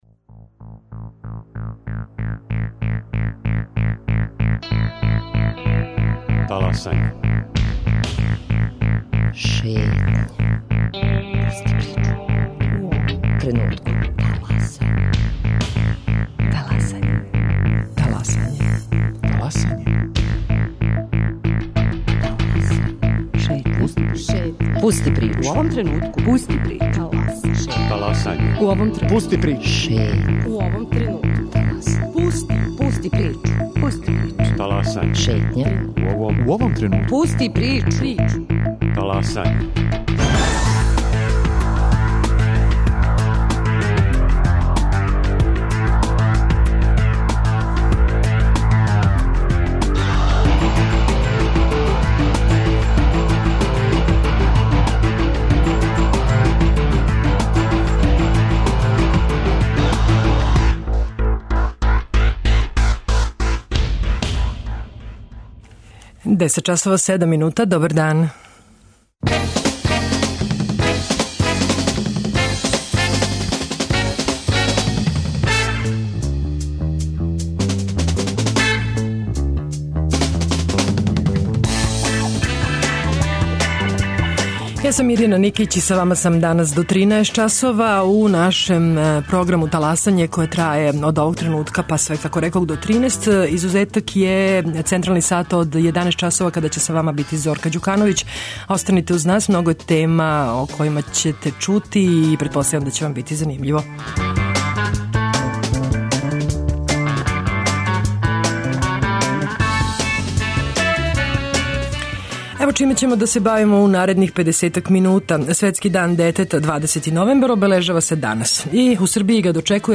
Гост Шетње је једна од најпознатијих књизевница за децу и младе